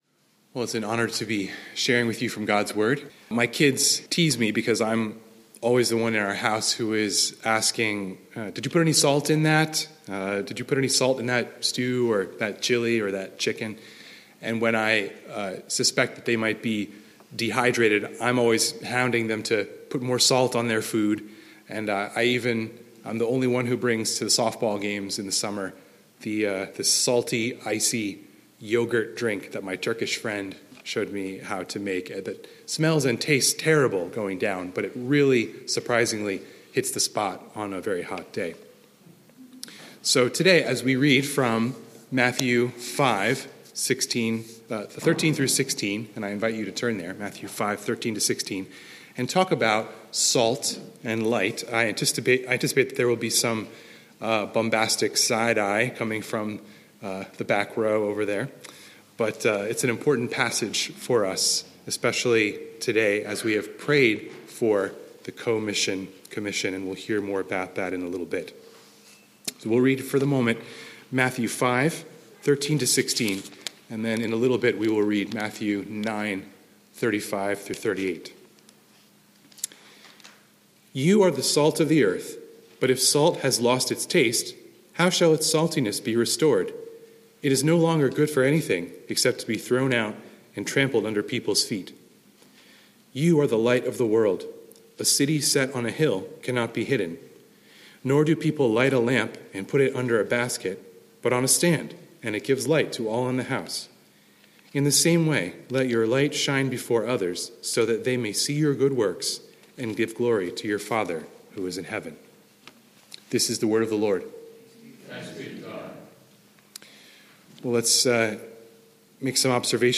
Sermon Text: Matthew 5:13–16; 9:35–38